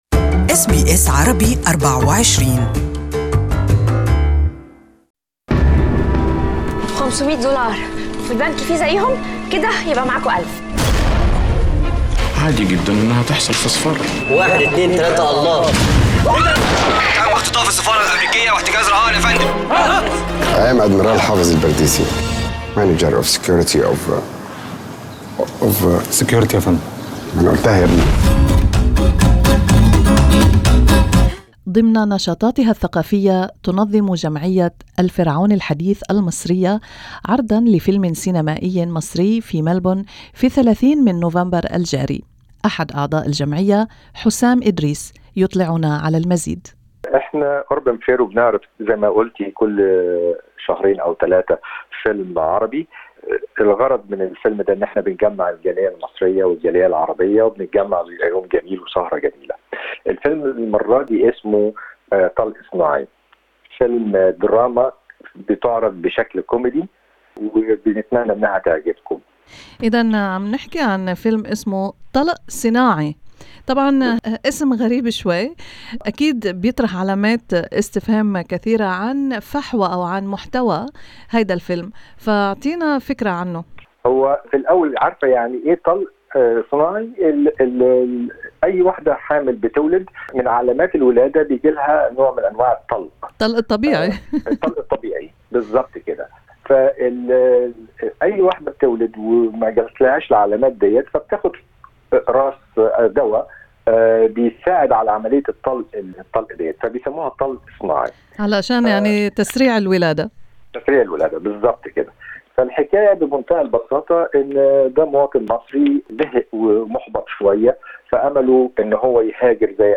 استمعوا إلى المقابلة الصوتية